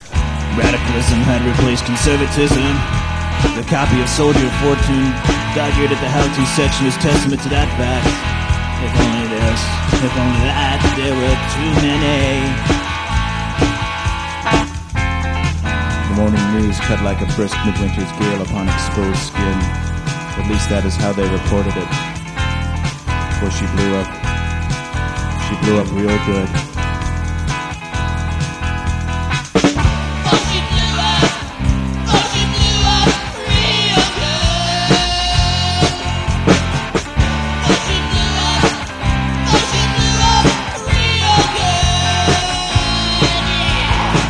And we all belt out the chorus.